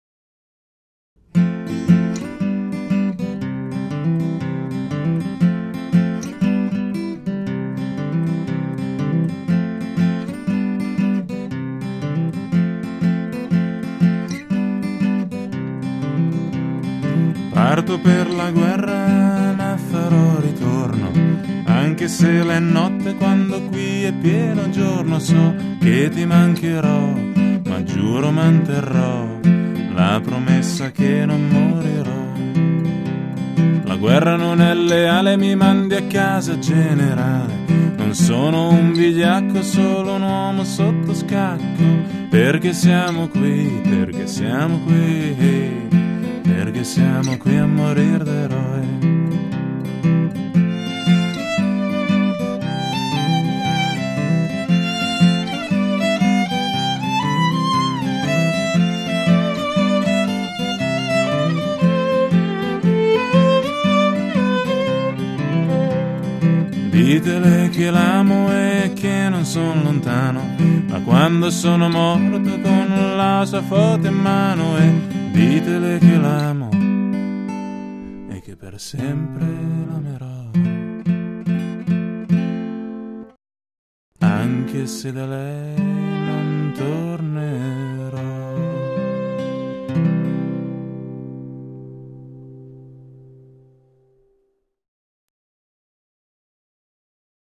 Violino